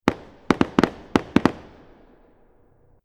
Fireworks #1 | TLIU Studios
Category: Explosions Mood: Festive Editor's Choice